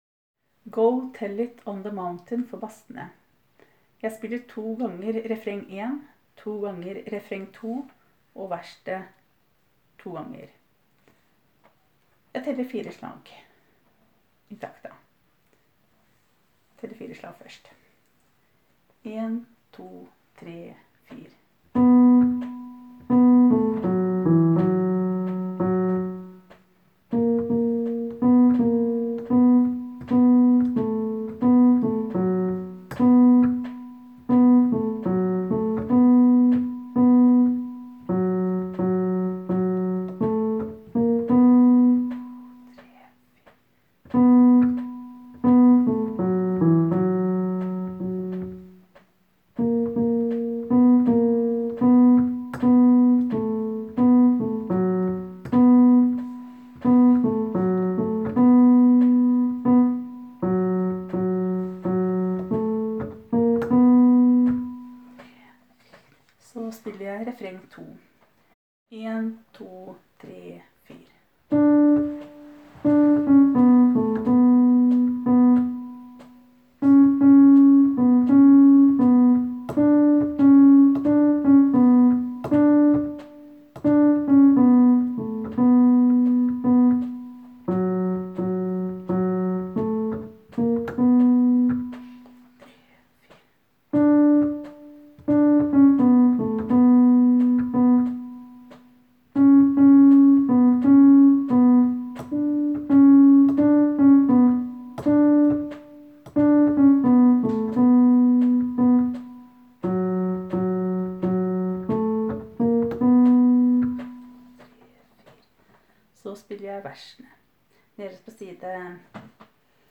Jul 2017 Bass (begge konserter)
Go-tell-it-on-the-mountain-Bassene.m4a